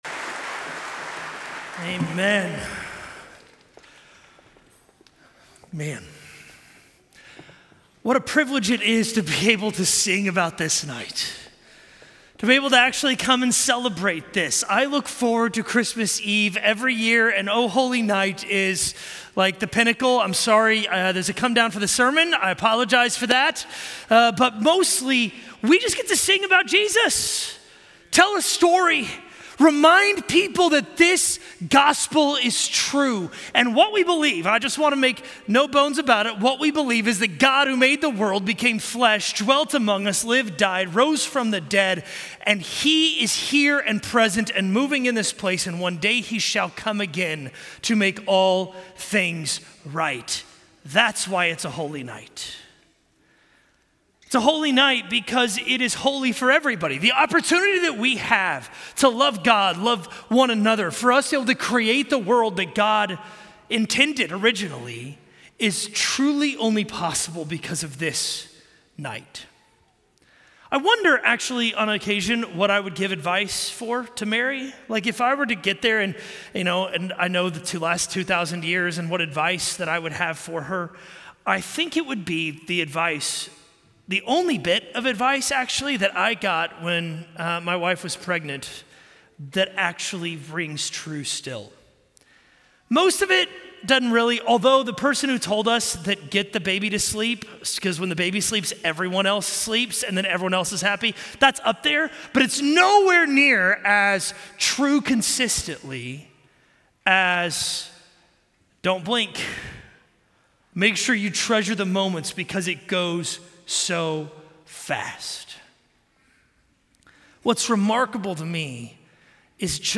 A message from the series "Advent."
4:00 PM Contemporary - Christmas Eve